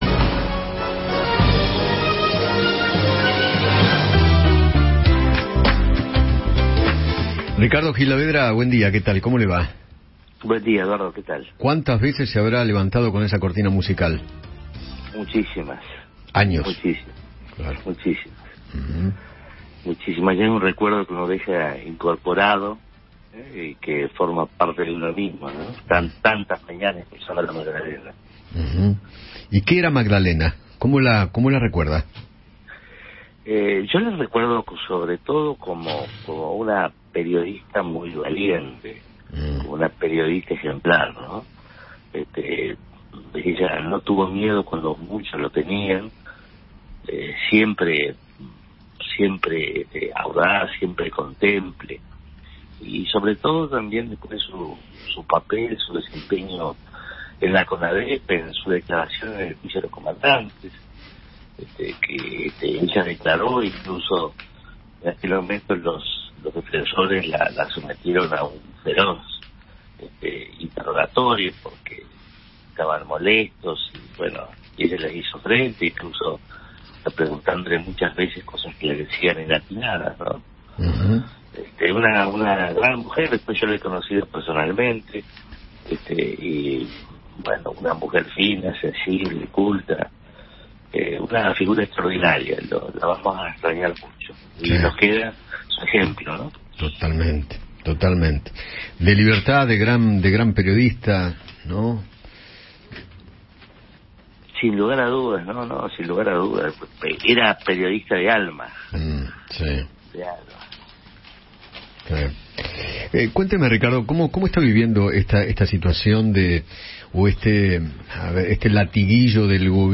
Ricardo Gil Lavedra, abogado constitucionalista, conversó con Eduardo Feinmann sobre los discursos de odio y, a la vez, recordó a Magdalena Ruiz Giñazú, quien falleció ayer al mediodía.